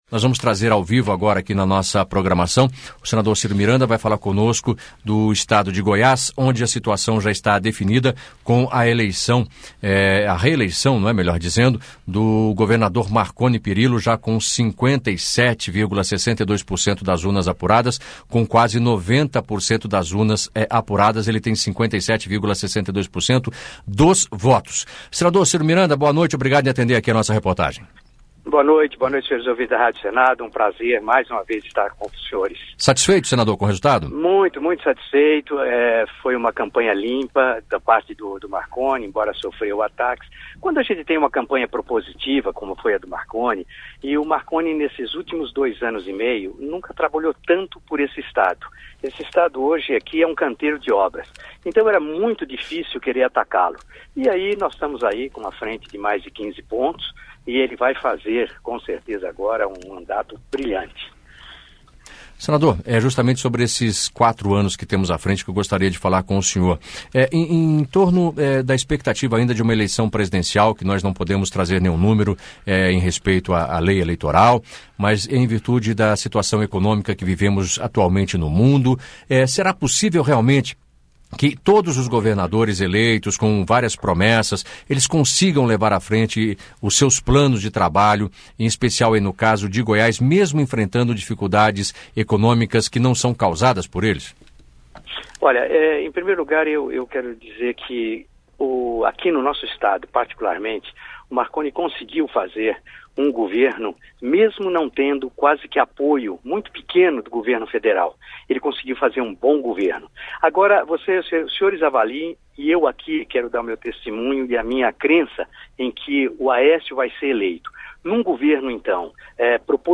Entrevista com o senador Cyro Miranda sobre os resultados das eleições em Goiás